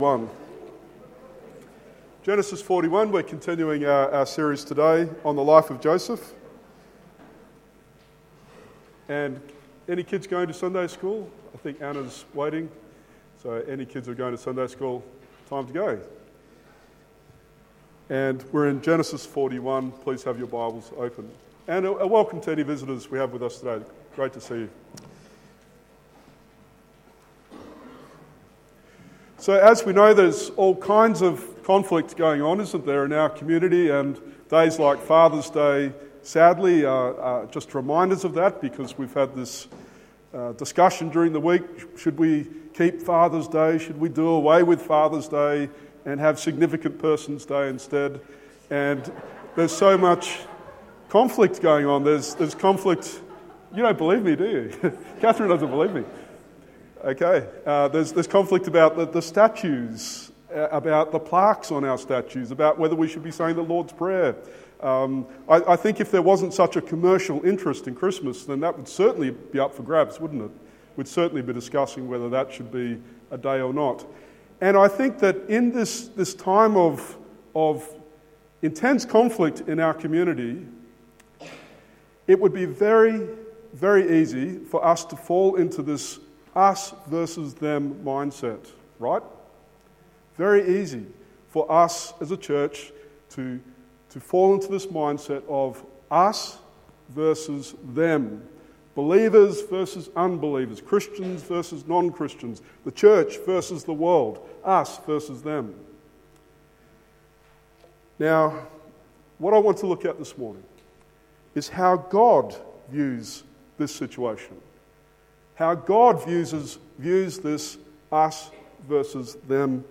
Genesis 41:1-56 Sermon